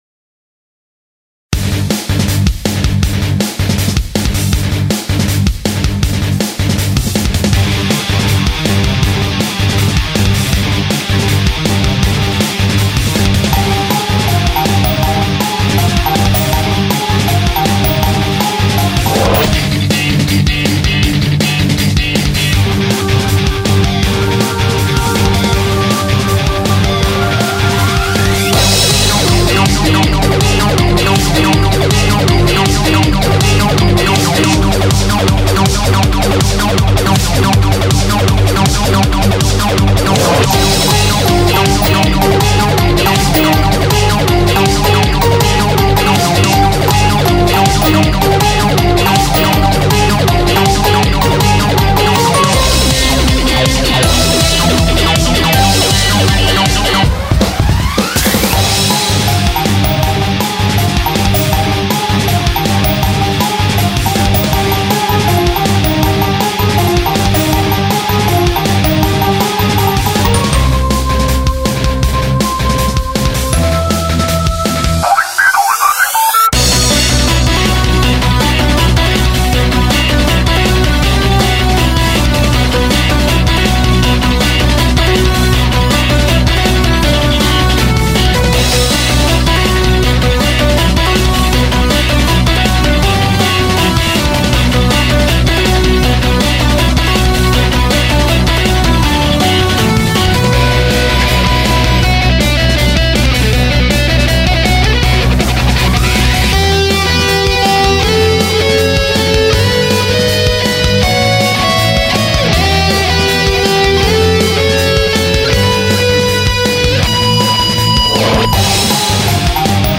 フリーBGM 戦闘曲